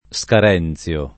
[ S kar $ n ZL o ]